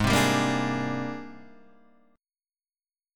G#M#11 chord